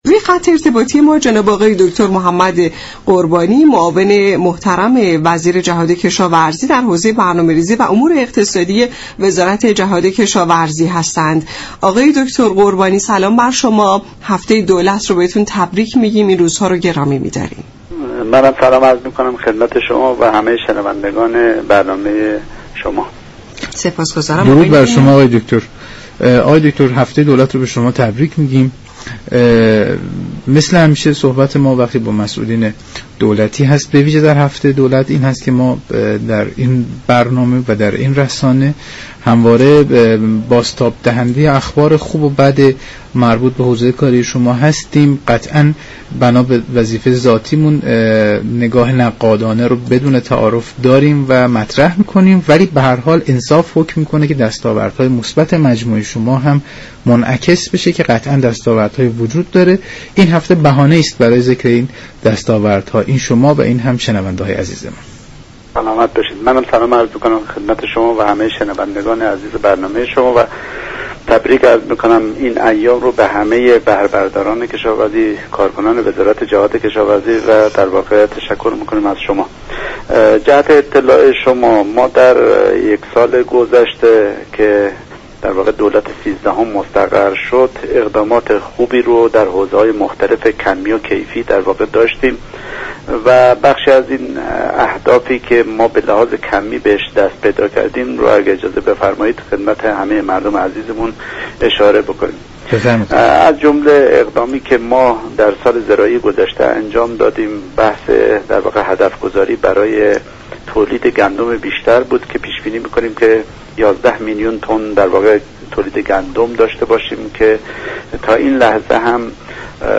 به گزارش شبكه رادیویی ایران، «محمد قربانی» معاون برنامه ریزی و امور اقتصادی جهاد كشاورزی در برنامه «نمودار» درباره دستاوردهای وزارت جهاد كشاورزی گفت: وزارت جهاد كشاورزی طی یكسال گذشته در حوزه های كیفی و كمی محصولات، اقدامات خوبی را انجام داده است.